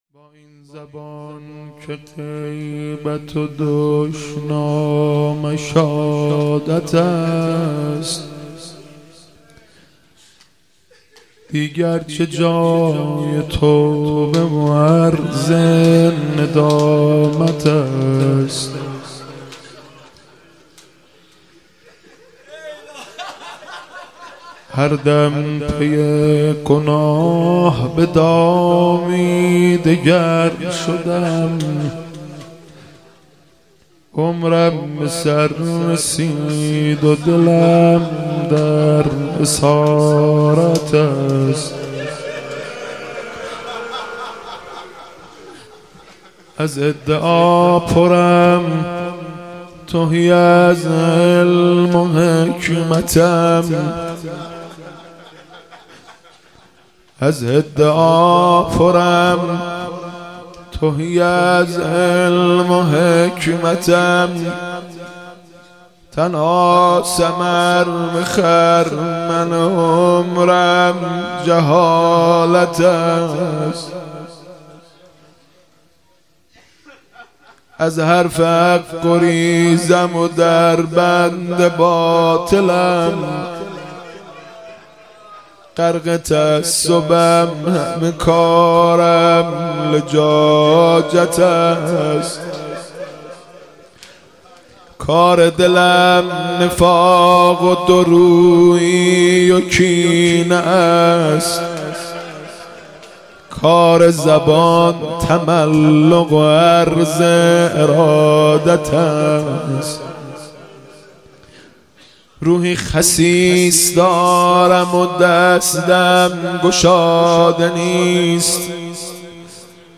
مداحی جدید